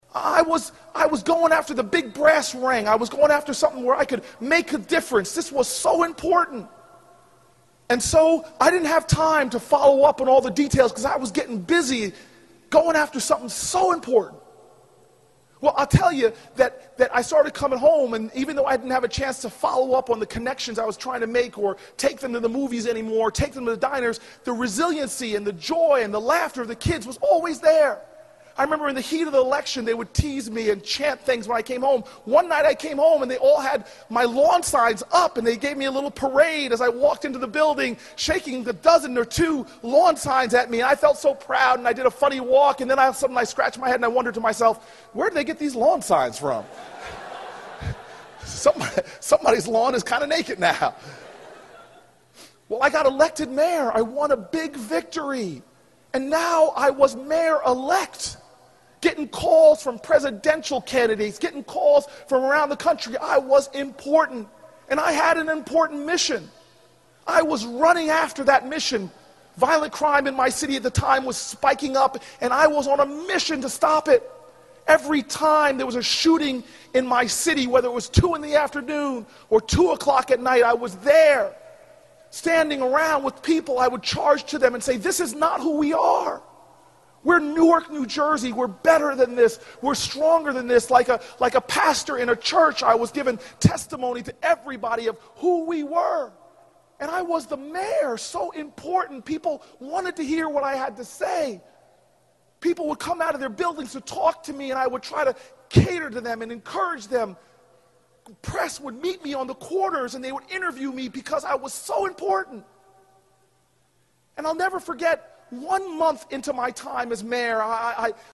公众人物毕业演讲第447期:科里布克2013年耶鲁大学(15) 听力文件下载—在线英语听力室